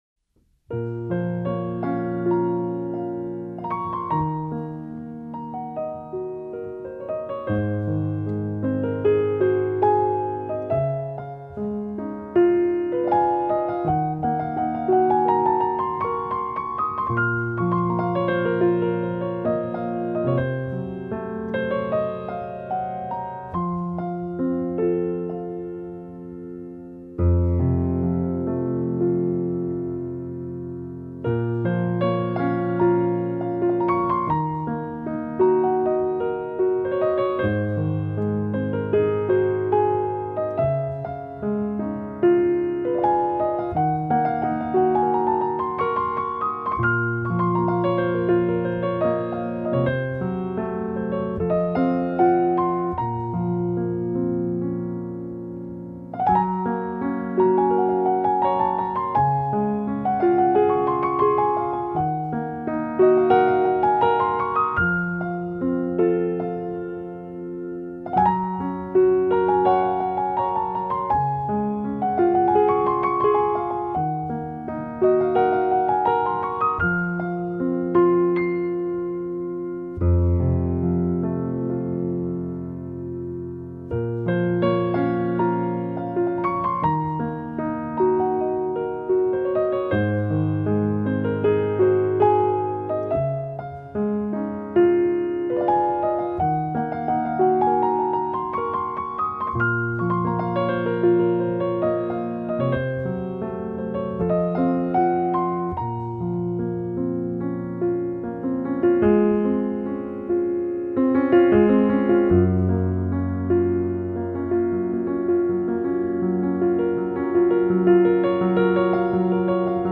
鋼琴獨奏版
淡淡哀愁的鋼琴音色，鋪陳一段段邂逅、愛與離別的故事，
用最溫柔、平和的曲調表現出來。